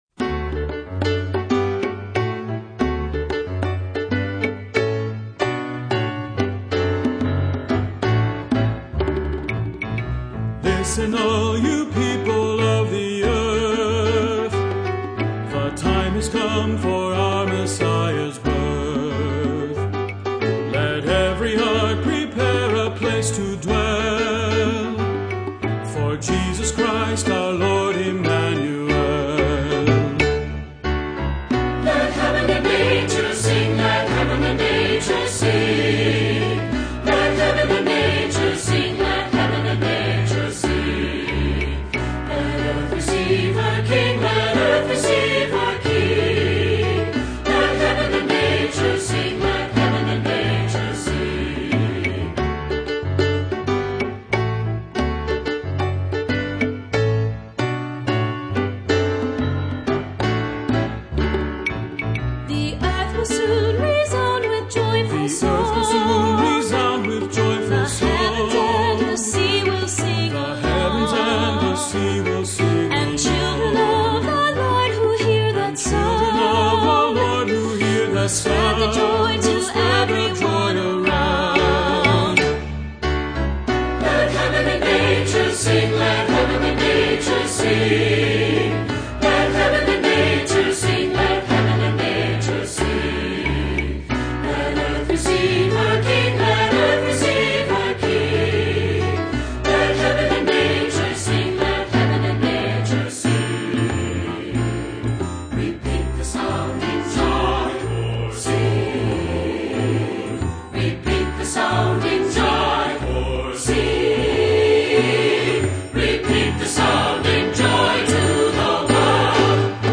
Voicing: SAB and Piano